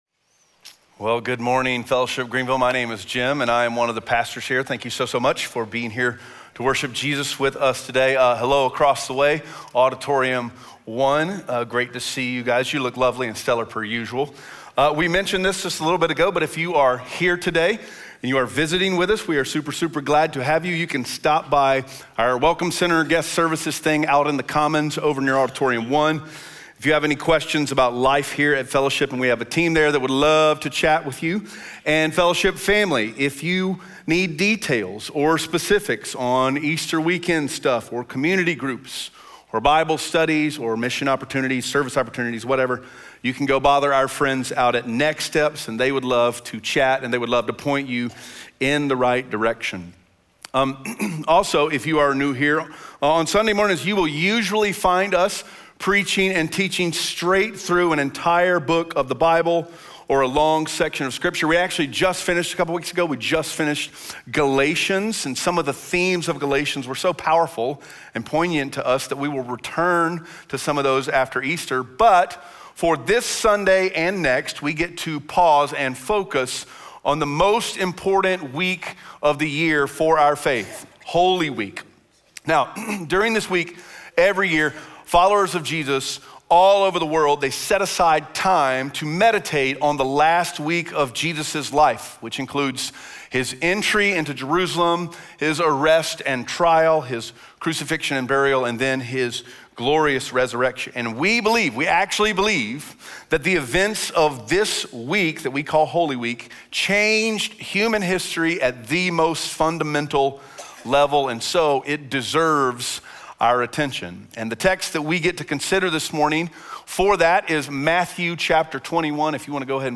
Matthew 21:1-11 Audio Sermon Notes (PDF) Ask a Question PASSAGE GUIDE Jesus’ entry into Jerusalem marks a profound moment of tension and irony—he is “kinged” on Sunday and crucified by Friday.